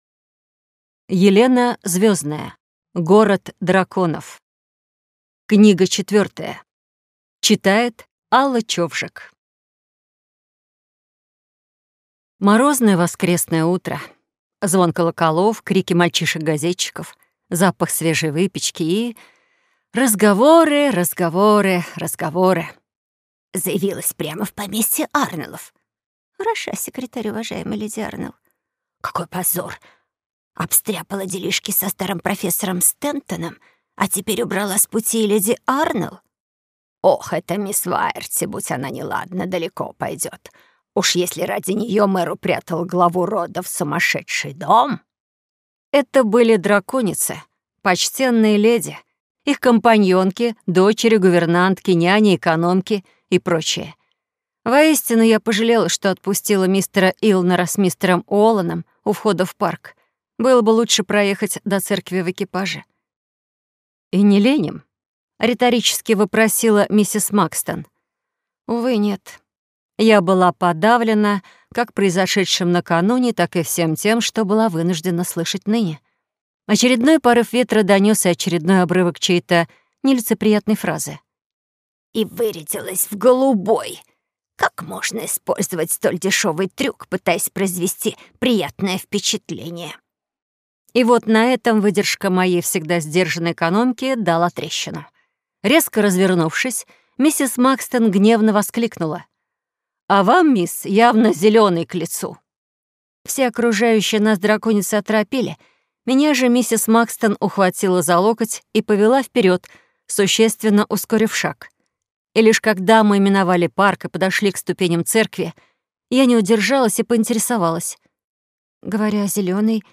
Аудиокнига Город драконов. Книга четвертая | Библиотека аудиокниг
Прослушать и бесплатно скачать фрагмент аудиокниги